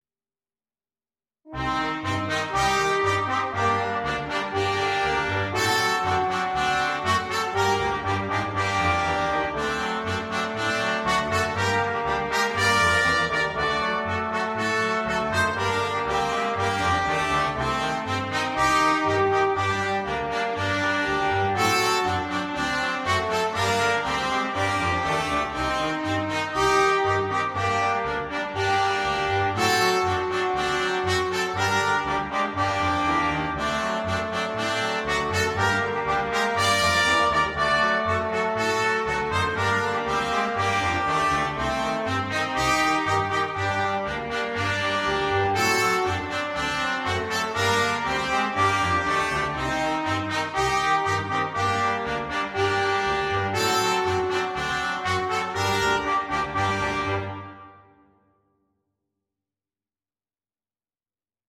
для брасс-квинтета
• автор музыки: русская народная песня.